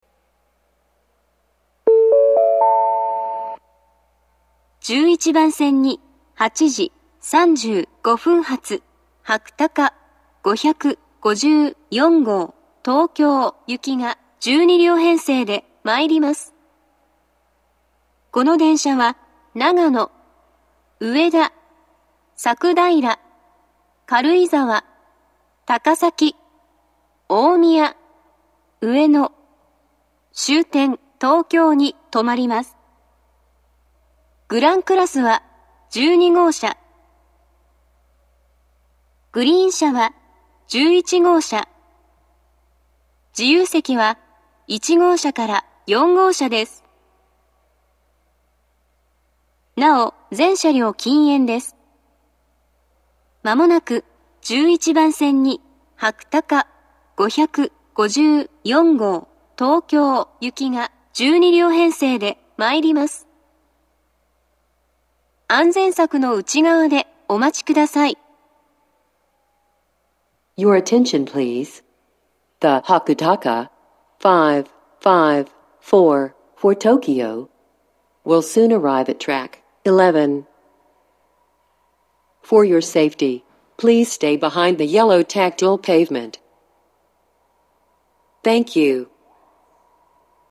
１１番線接近放送